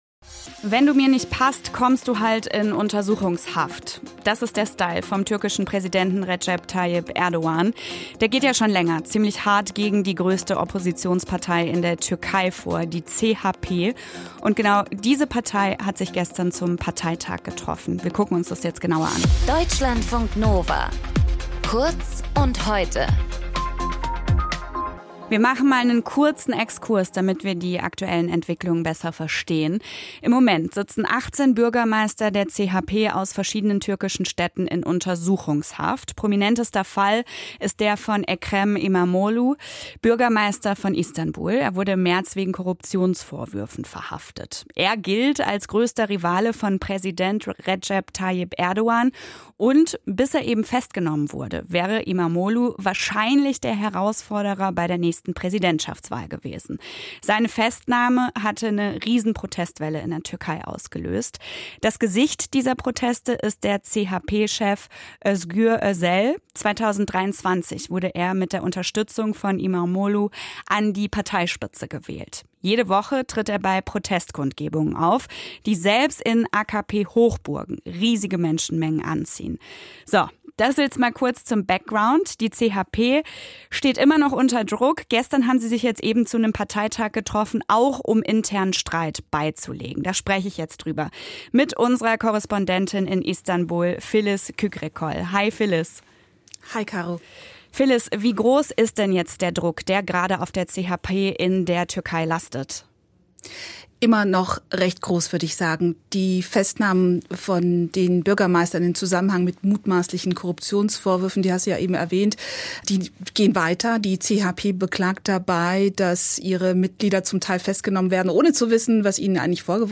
Erdogans Willkür wird Folgen für Deutschland haben (Kommentar)